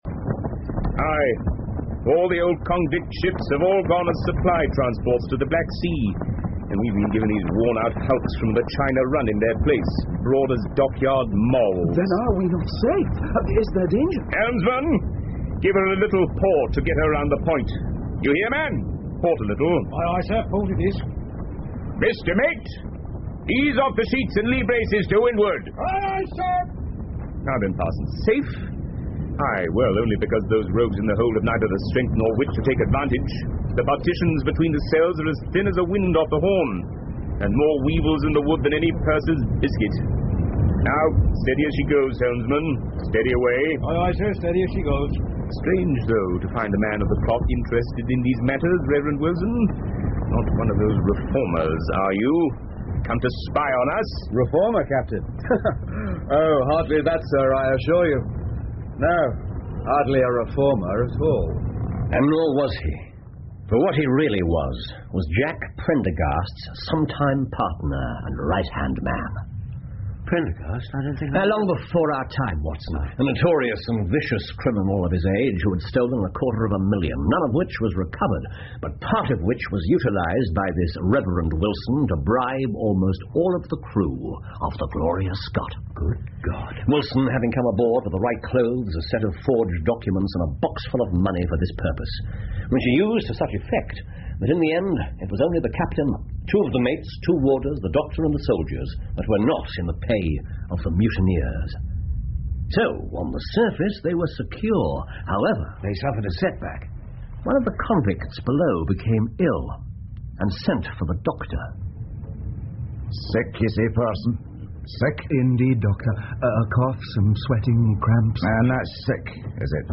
福尔摩斯广播剧 The Gloria Scott 7 听力文件下载—在线英语听力室